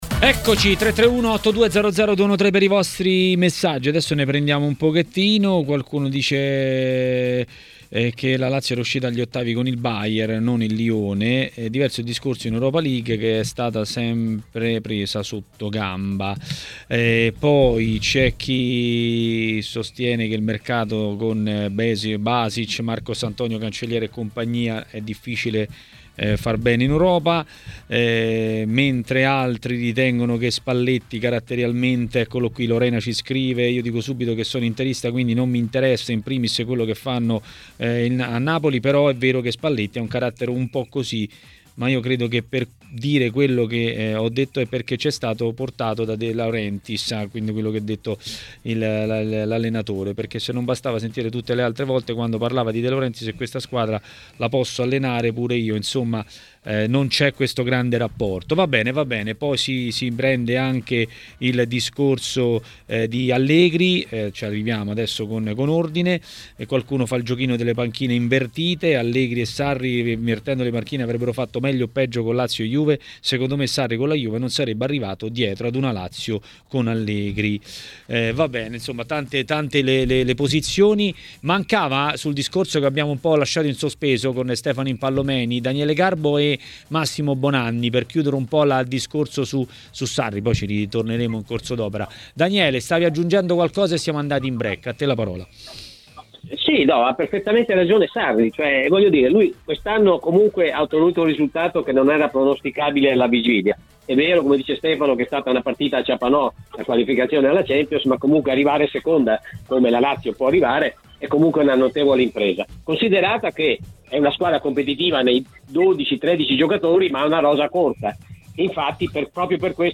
Ospite di TMW Radio